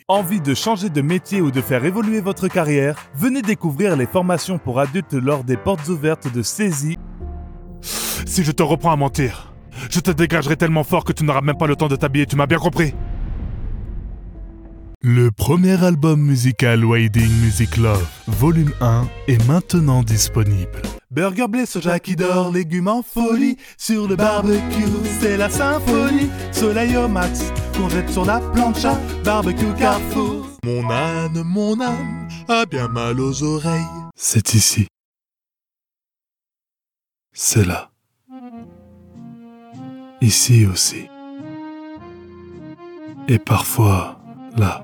Never any Artificial Voices used, unlike other sites.
French (Parisienne)
Yng Adult (18-29) | Adult (30-50)